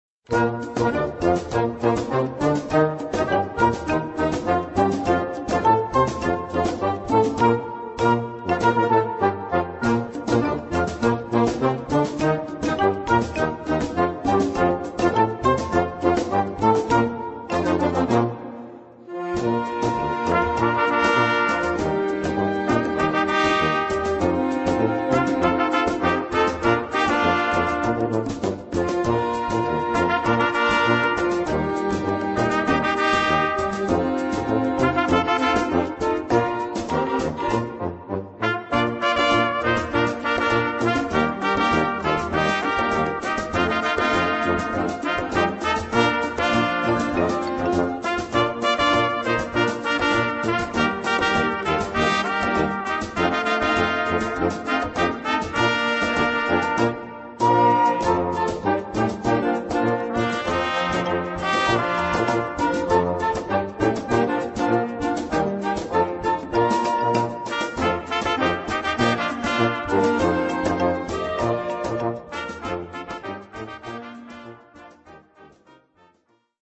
Gattung: Solo für Trompete
Besetzung: Blasorchester